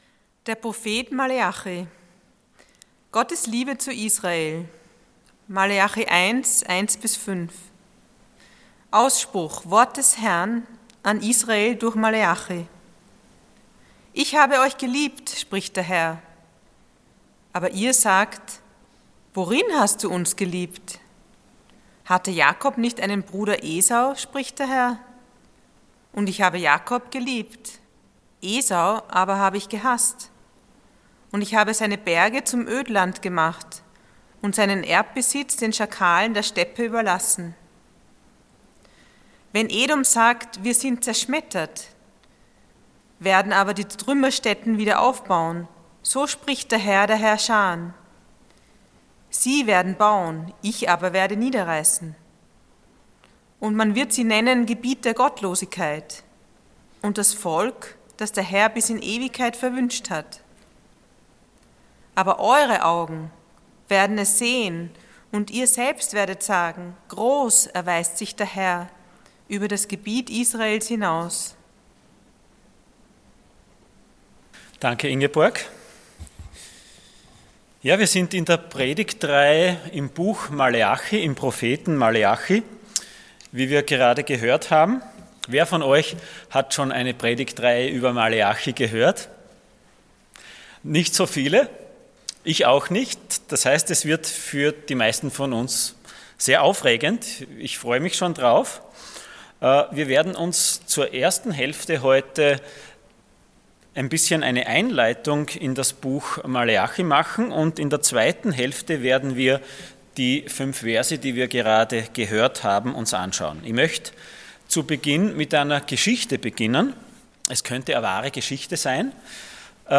Passage: Malachi 1:1-5 Dienstart: Sonntag Morgen